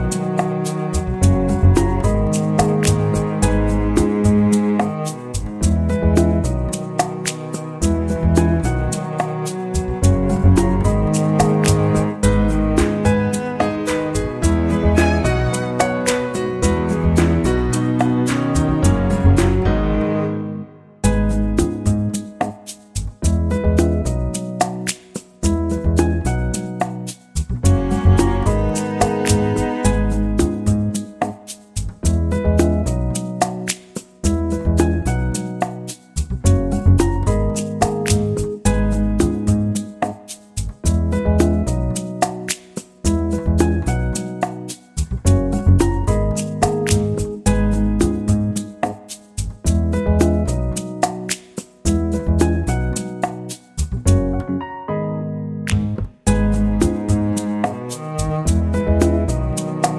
Original Female Key